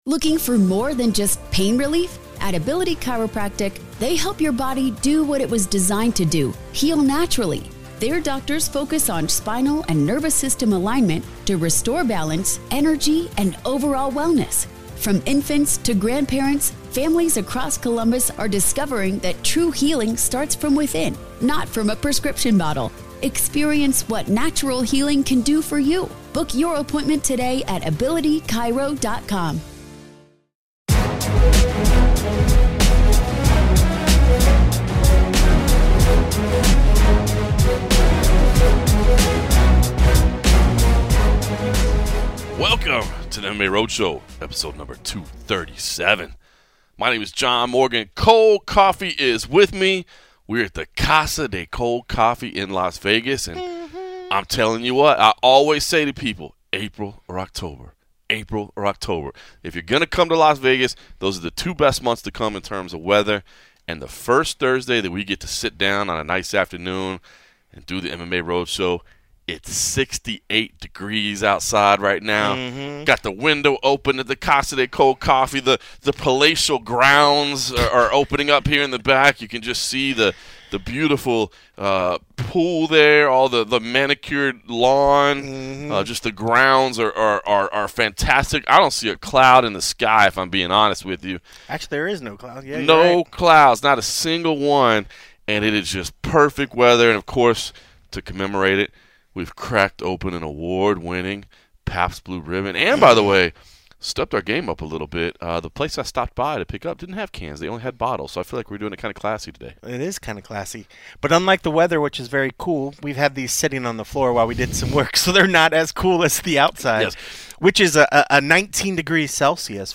media day interviews